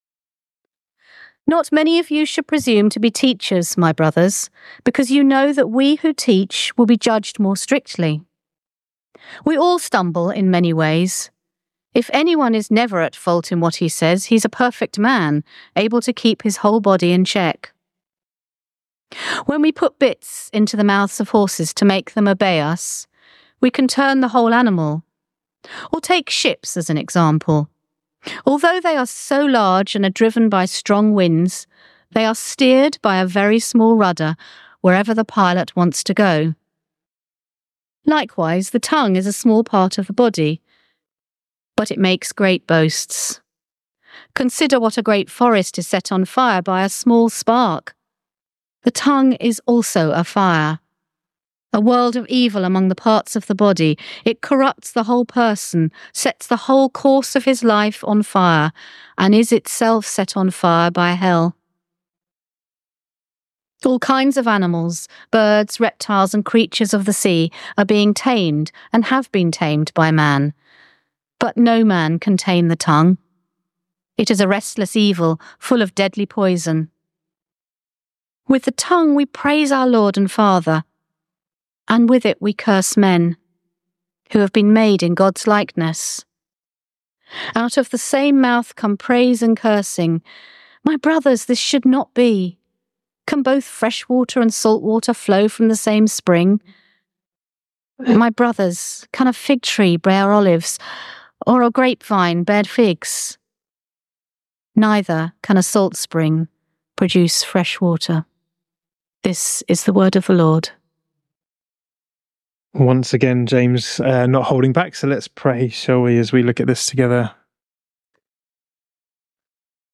St Mary’s, Slaugham – Holy Communion